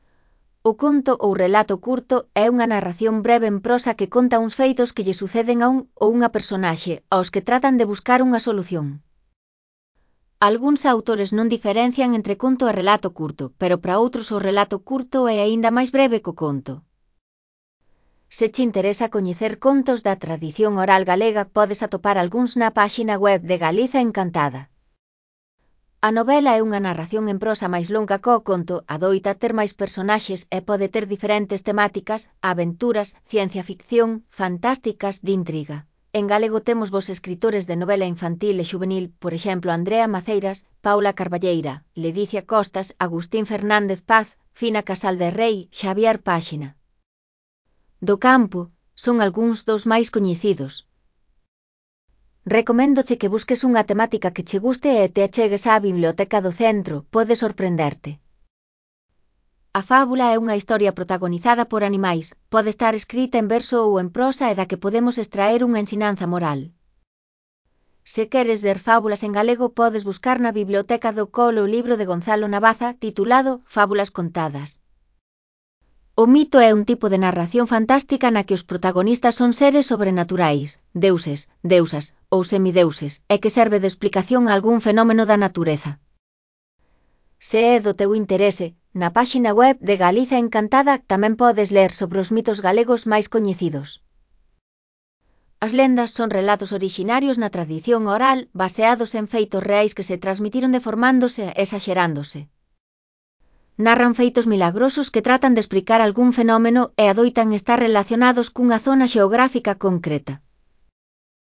Elaboración propia (Proxecto cREAgal) con apoio de IA, voz sintética xerada co modelo Celtia. . Principais textos narrativos. (CC BY-NC-SA)